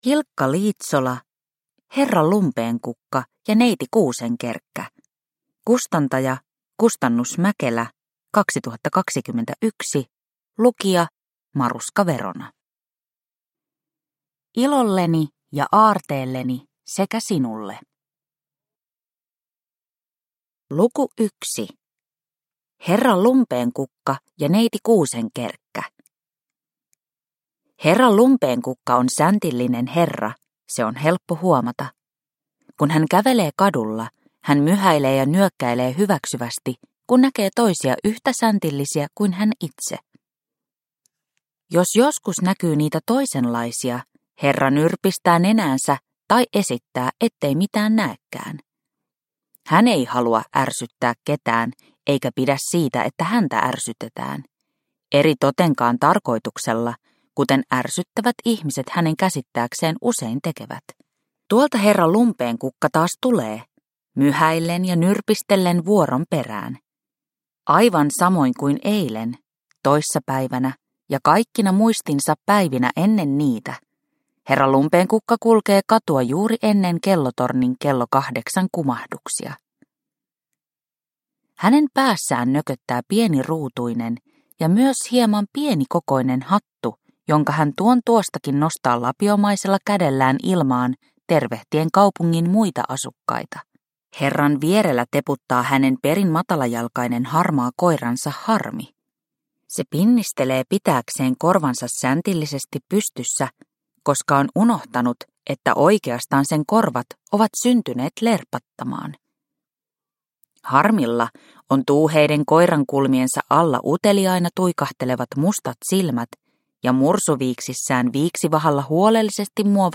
Herra Lumpeenkukka ja neiti Kuusenkerkkä – Ljudbok – Laddas ner